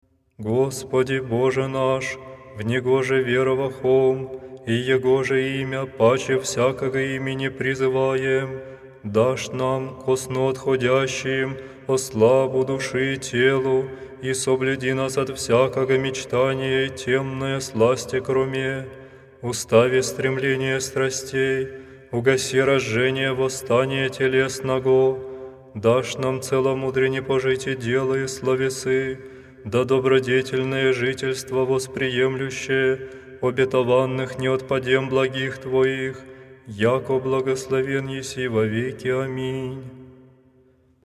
читает диакон
Молитвы вечерние Натиснете за преглед на изображението 8 Прослушай всички композиции от този стил музика Прослушай всички композиции от този стил музика в случаен ред Речитатив